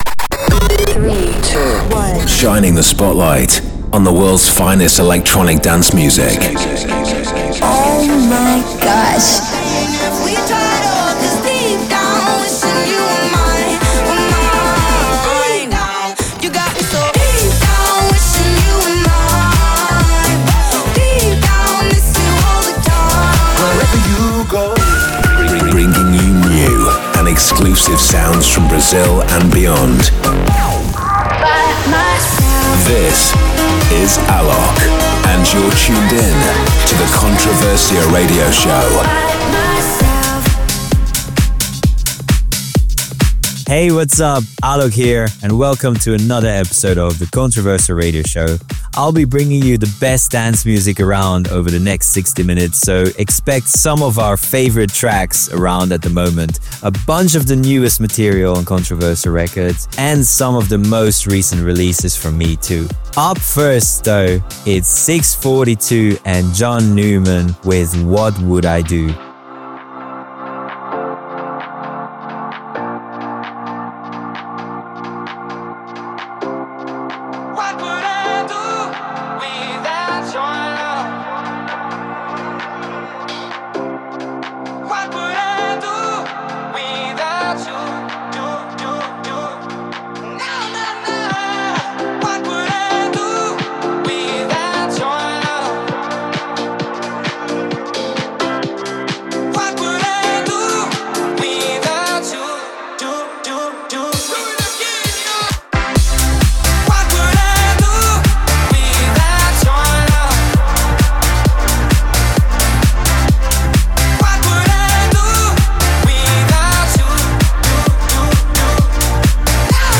Also find other EDM Livesets, DJ Mixes and Radio Show
The weekly radio show